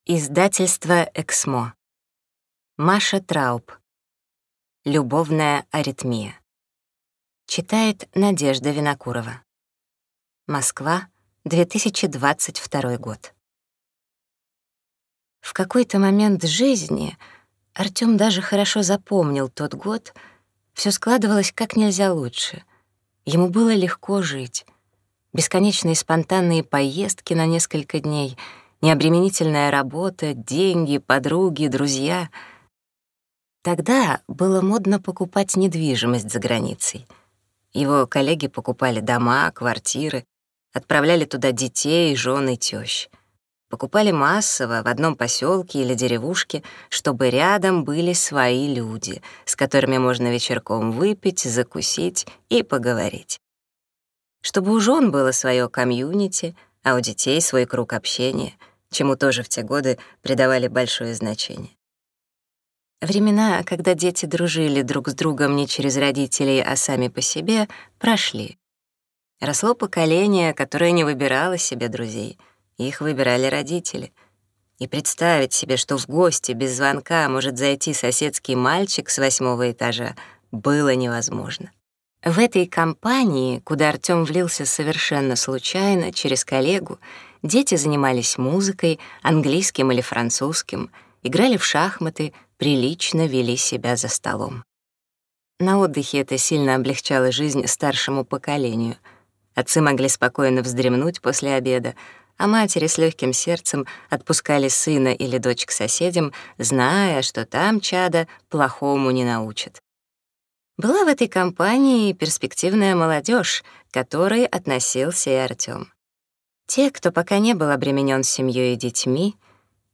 Аудиокнига Любовная аритмия | Библиотека аудиокниг